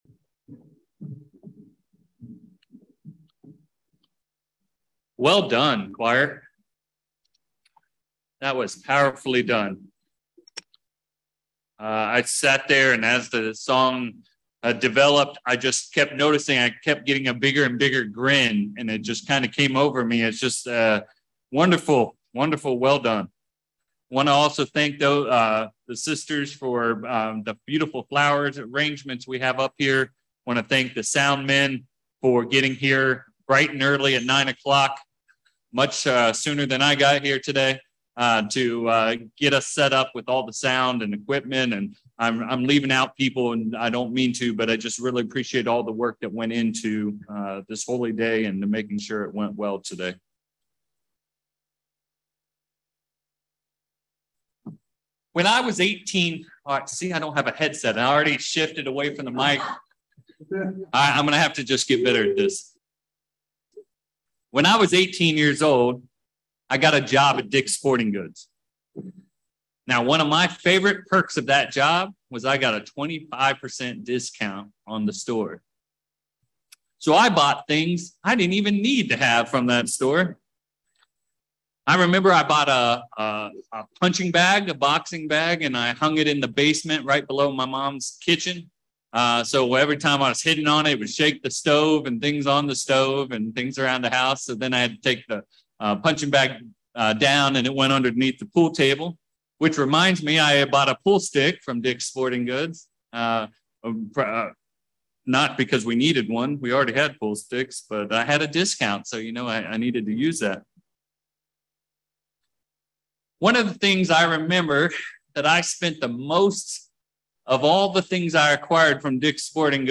Satan uses similar methods to tempt Christians to sin. In this sermon, we’ll discuss six similarities to these methods and dive into the temptation process that the Apostle James outlines.
Sermons